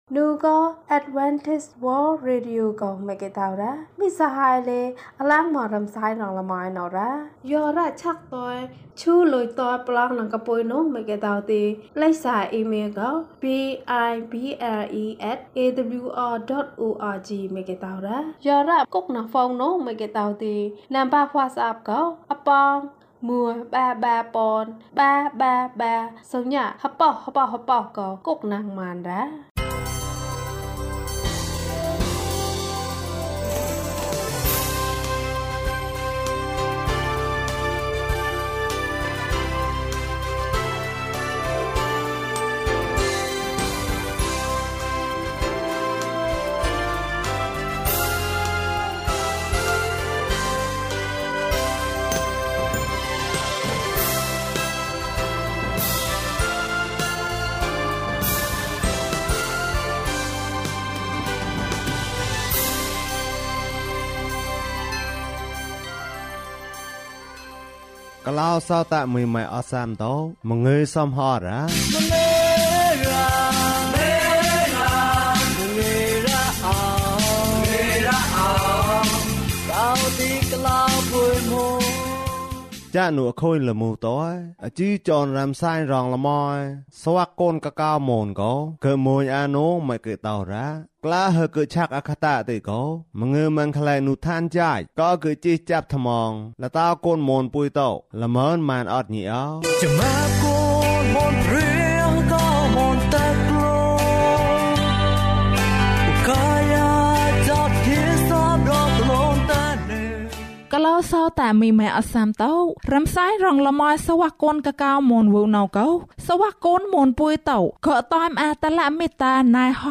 သခင်ယေရှုသည် ကျွန်ုပ်အား အသက်တာသစ်ပေး၊ ကျန်းမာခြင်းအကြောင်းအရာ။ ဓမ္မသီချင်း။ တရားဒေသနာ။